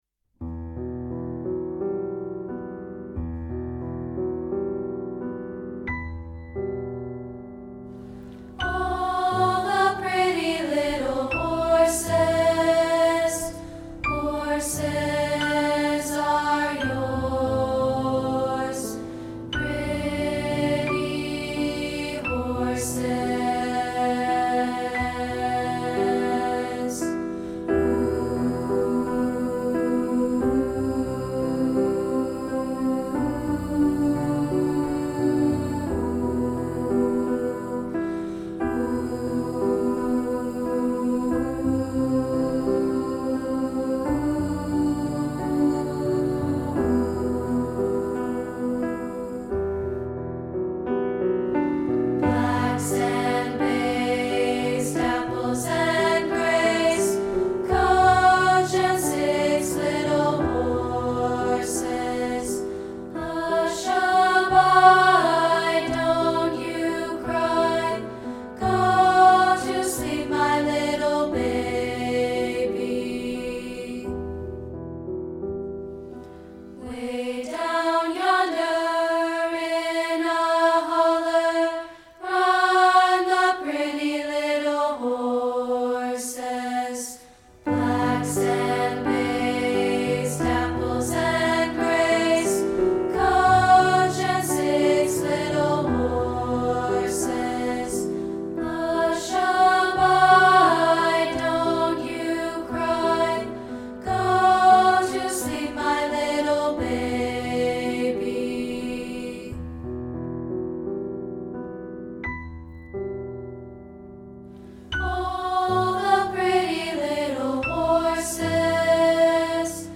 including this rehearsal track of part 2, isolated.